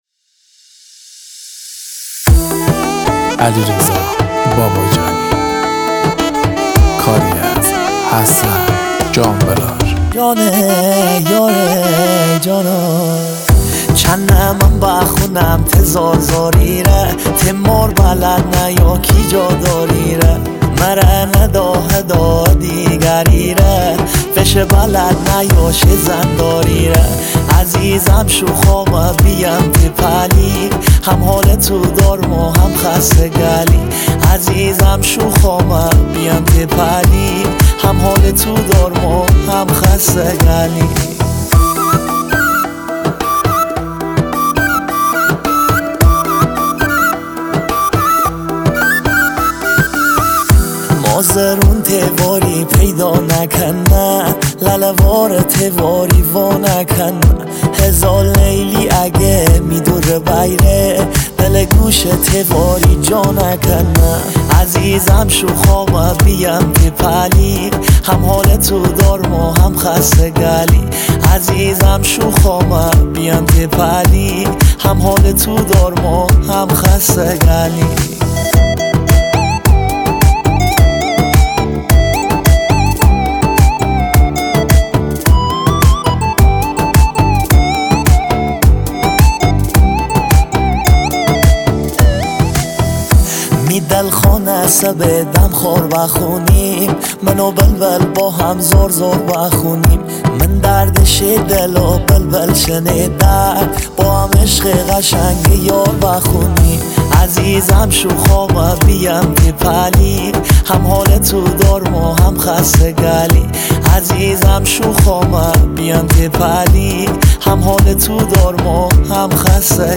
شاد